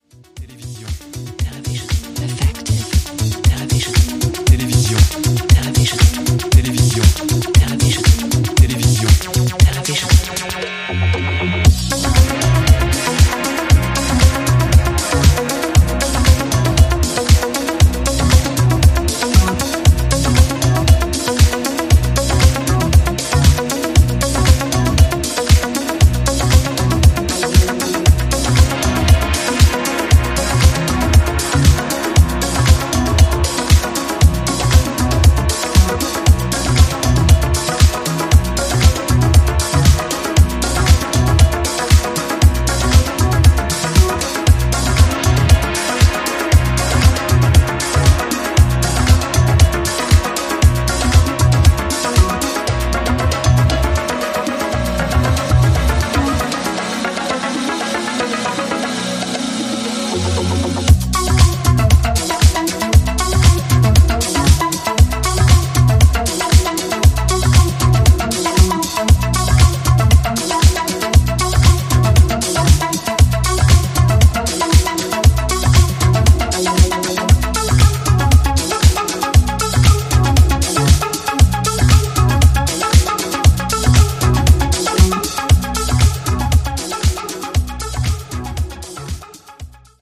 バレアリックやアシッド等の要素をセンス良くブレンドさせた、